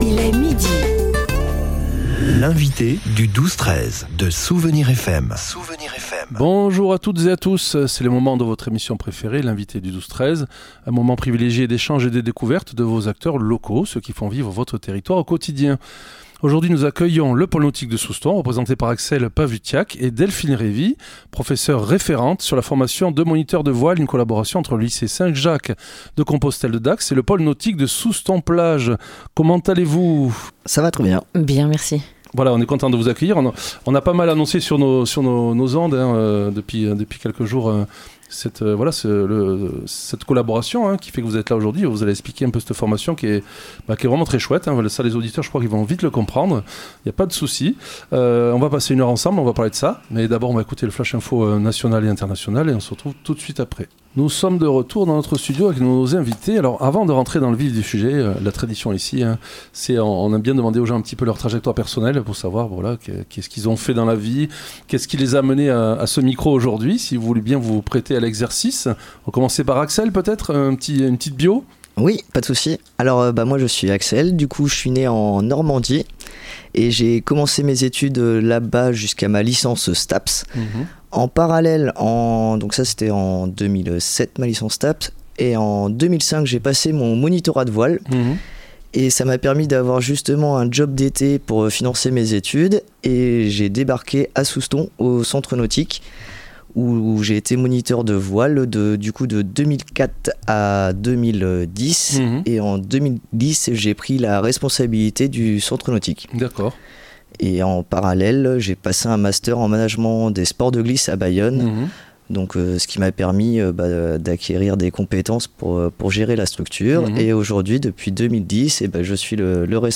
Nos invités ont partagé avec nous leur histoire personnelle et nous ont fait découvrir leur structure.